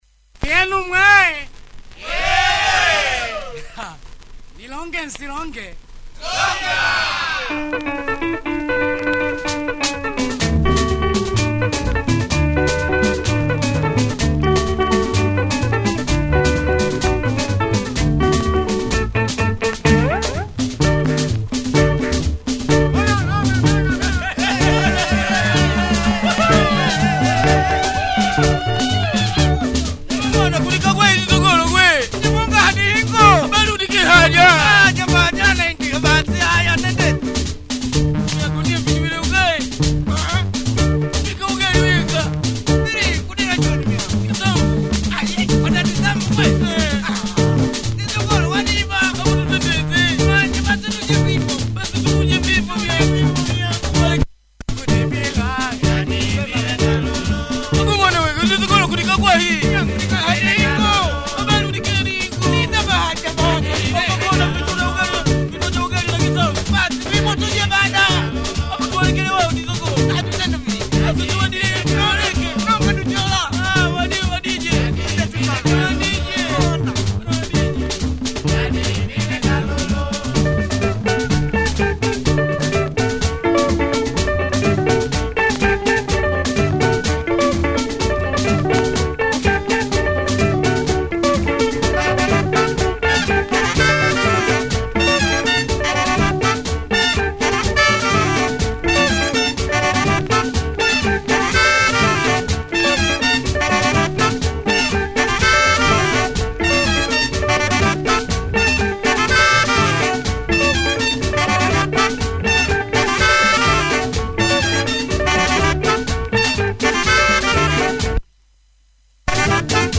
Bouncy soukouss from this great group, great groove.